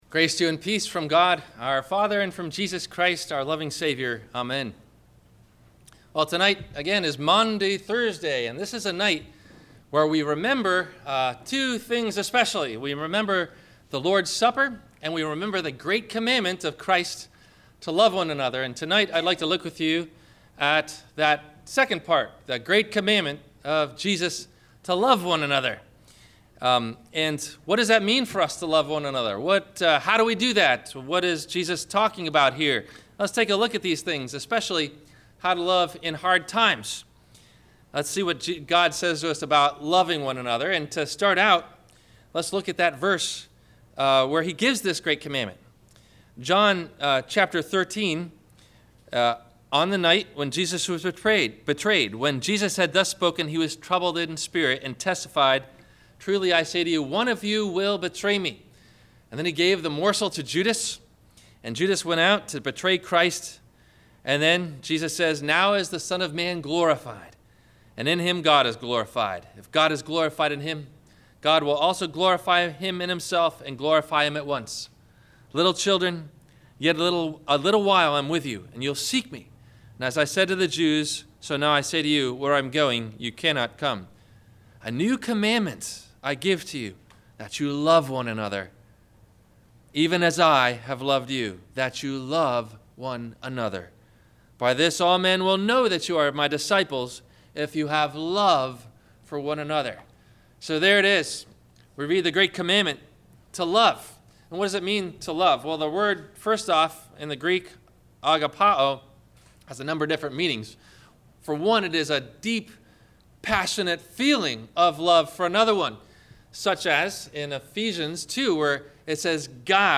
The Great Commandment, to Love One Another – Maundy Thursday – Sermon – April 02 2015